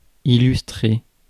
Prononciation
Prononciation France: IPA: [i.lys.tʁe] Le mot recherché trouvé avec ces langues de source: français Les traductions n’ont pas été trouvées pour la langue de destination choisie.